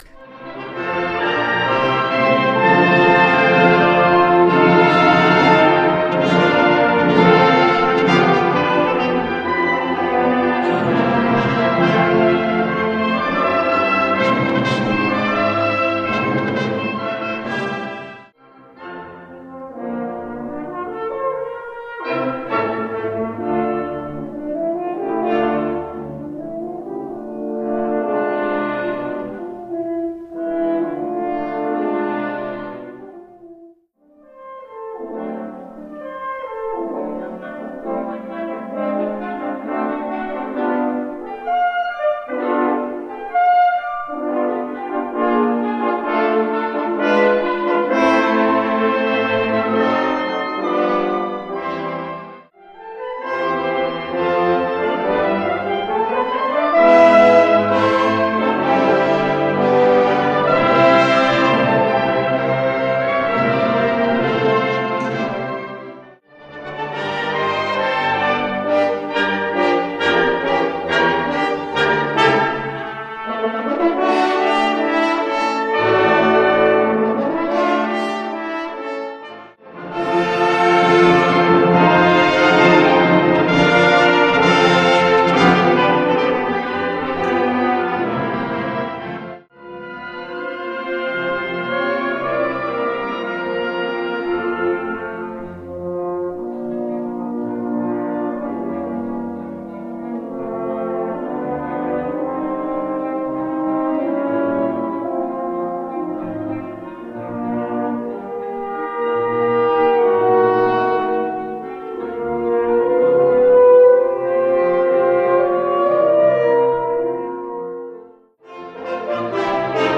Solo für 4 Hörner
Blasorchester